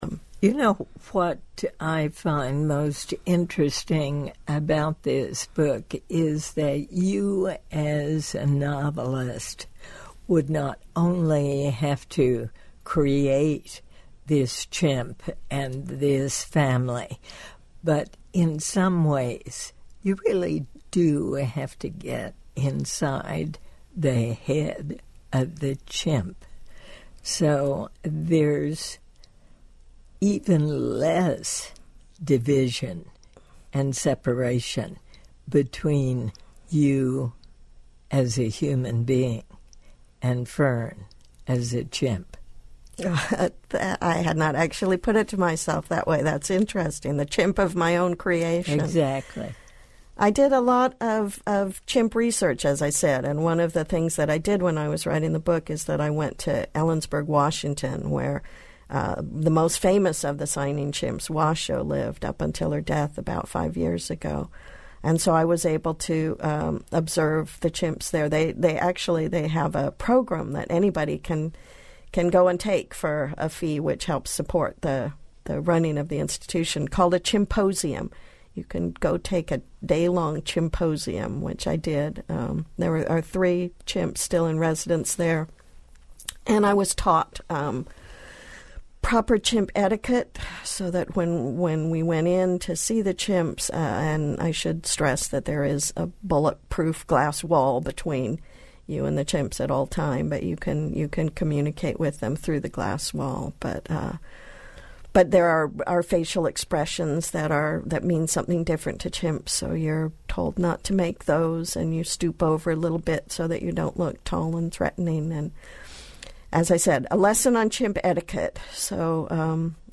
In her interview with Diane Rehm of NPR, author Karen Joy Fowler credits her visit to CHCI for helping her understand chimpanzees for her novel We Are All Completely Beside Ourselves.